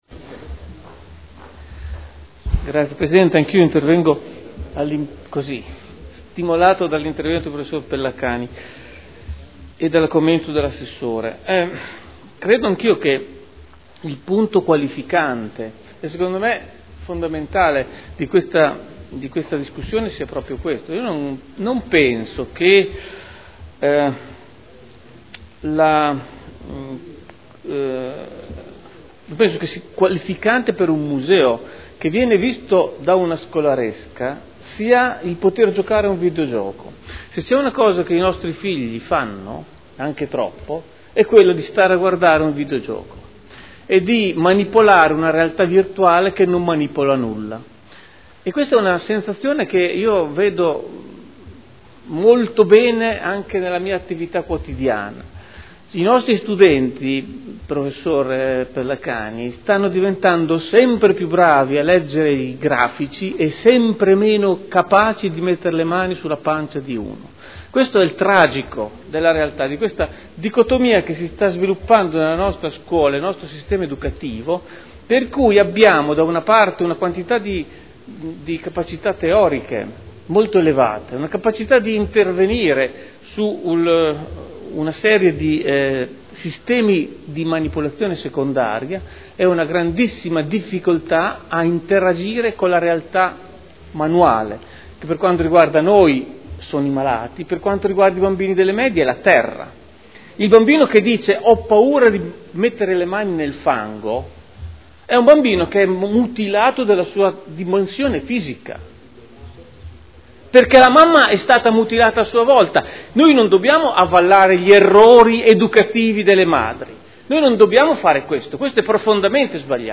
Seduta del 08/04/2013 Dibattito.